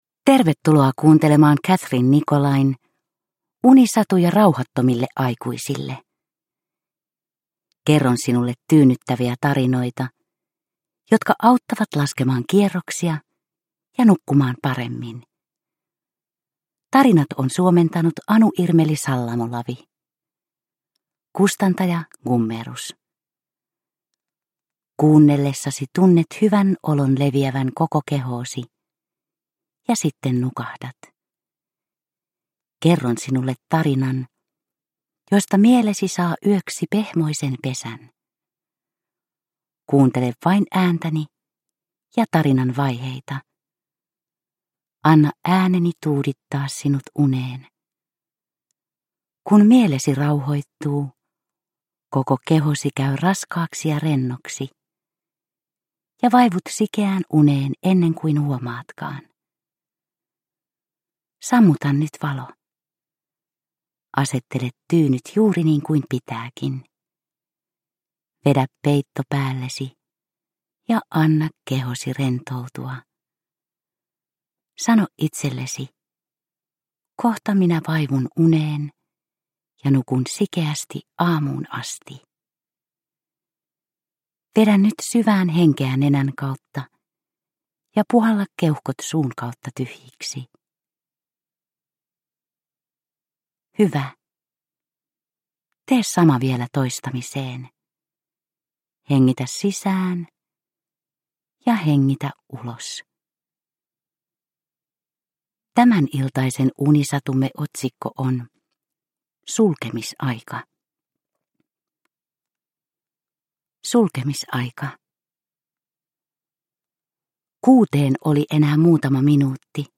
Unisatuja rauhattomille aikuisille 24 - Sulkemisaika – Ljudbok – Laddas ner